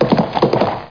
hooves2.mp3